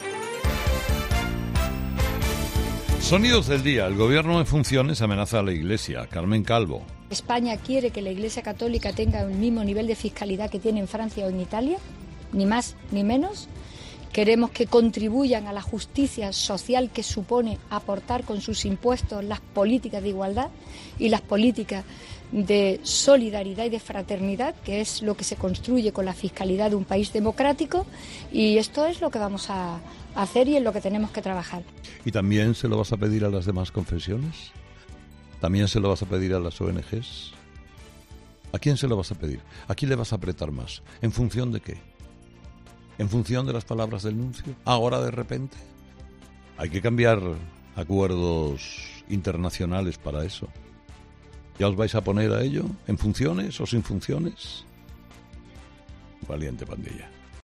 En su monólogo de este lunes, Carlos Herrera ha respondido con firmenza a la vicepresidenta.